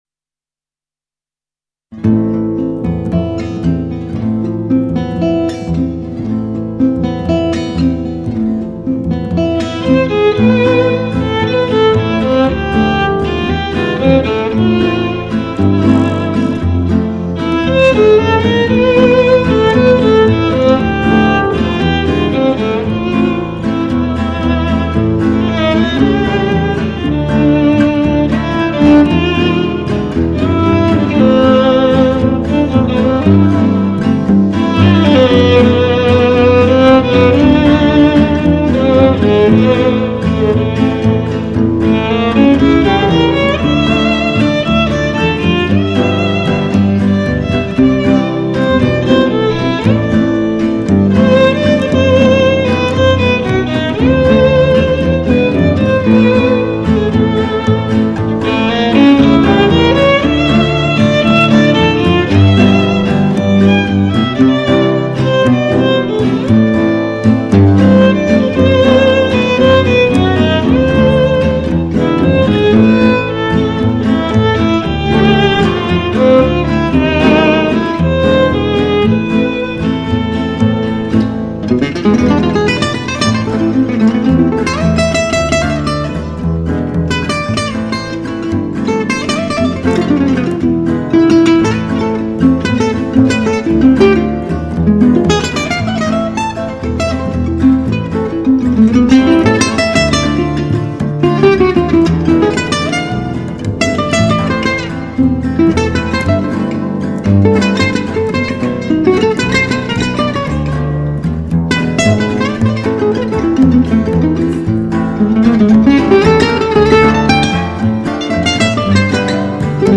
Standard jazz swing manouche